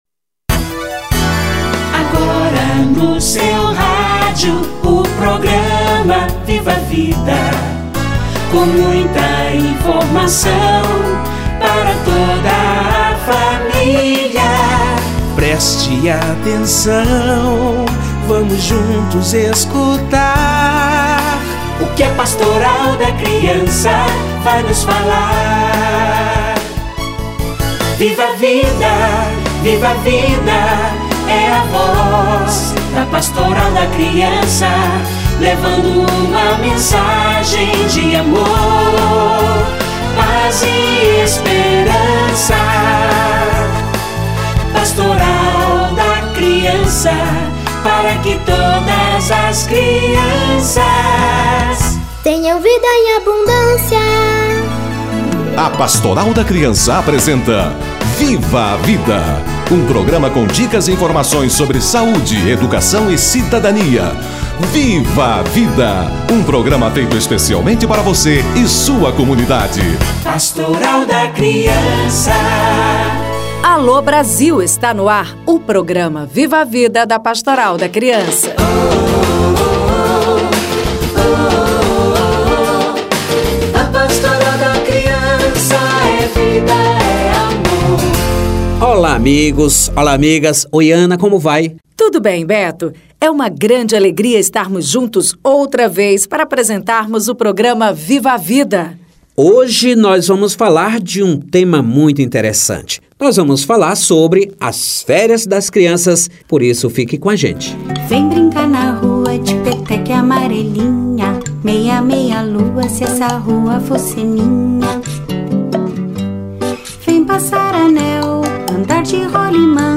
Criatividade nas férias - Entrevista